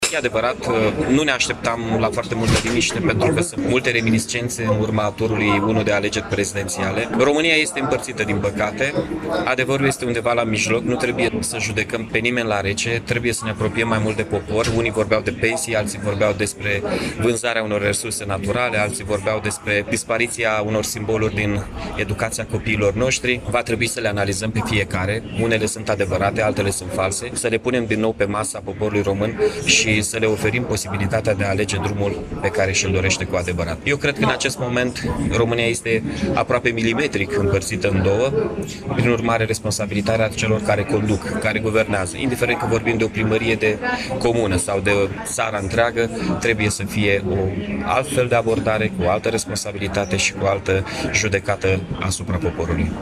Peste 5.000 de persoane se află în Piaţa Unirii din Iaşi, pentru a celebra 166 de ani de la înfăptuirea Unirii Principatelor Române.